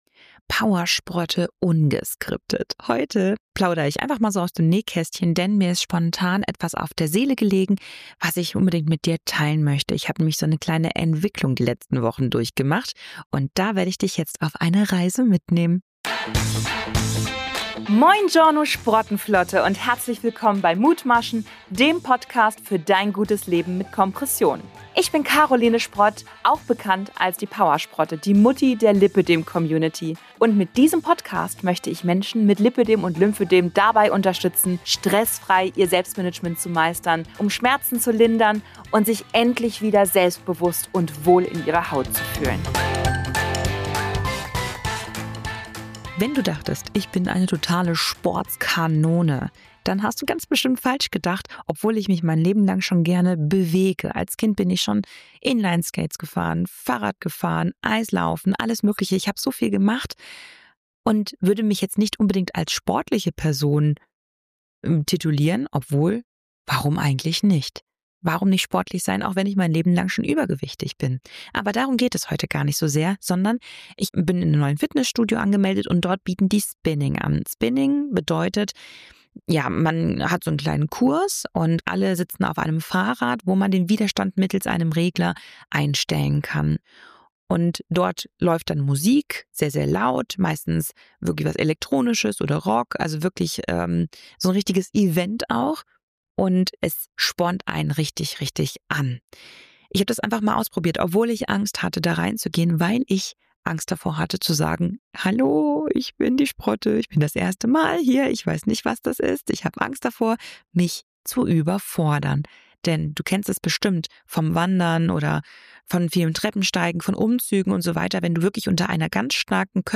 Ich nehme dich ungeskriptet mit in ein Erlebnis, das mich selbst überrascht hat. Vor ein paar Wochen bin ich zum ersten Mal in einen Spinning-Kurs gegangen.